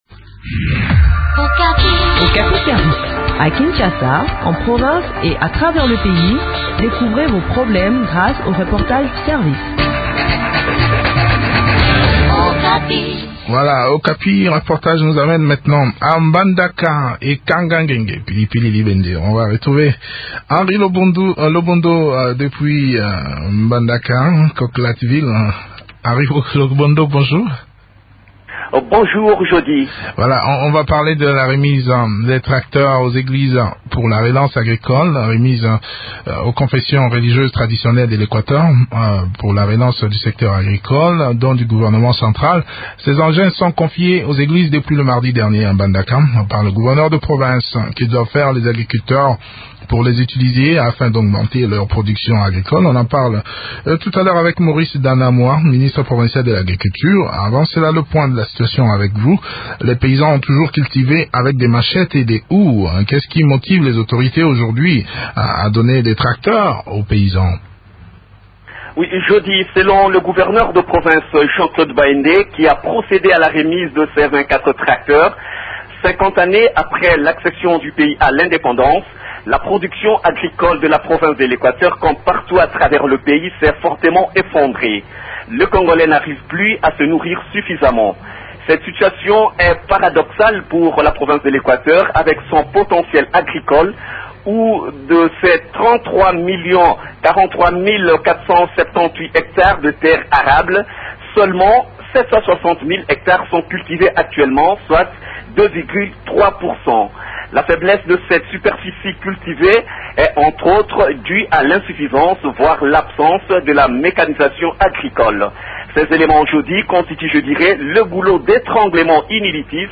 s’entretient sur les modalités de location de ces engins avec Maurice Danamwa, ministre provincial de l’agriculture.